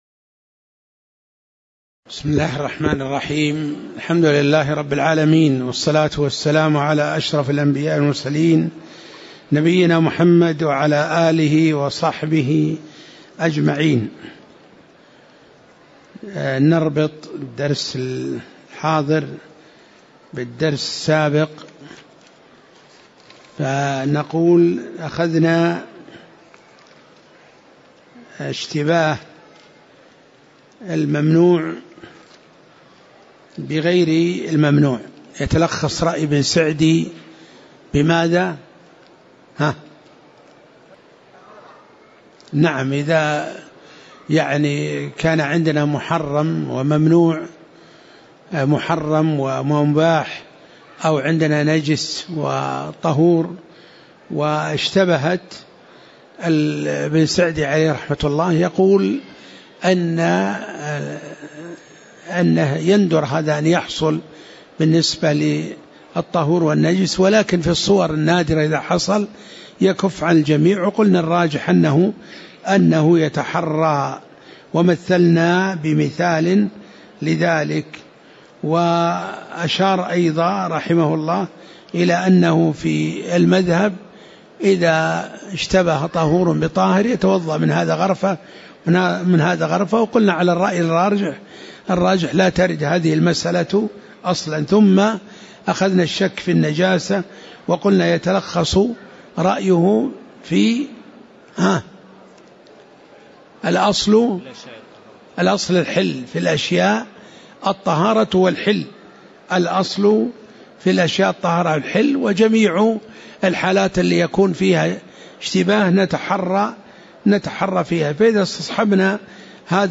تاريخ النشر ١٤ شوال ١٤٣٨ هـ المكان: المسجد النبوي الشيخ